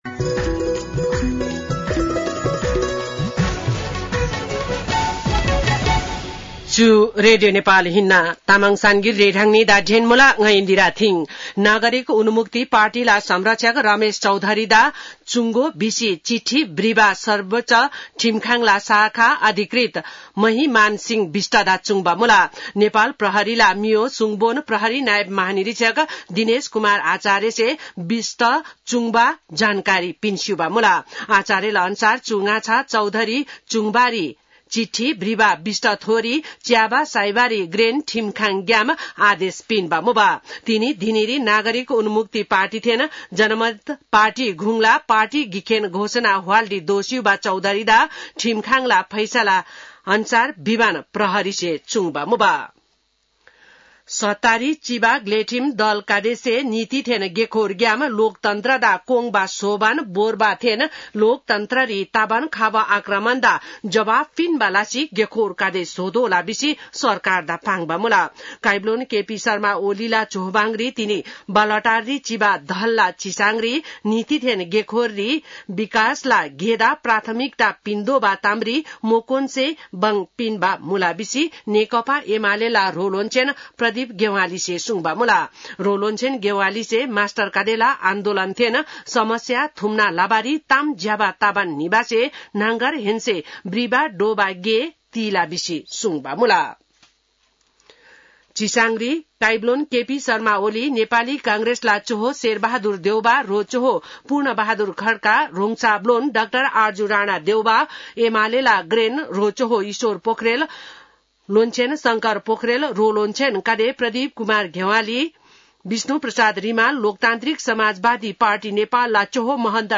तामाङ भाषाको समाचार : १७ वैशाख , २०८२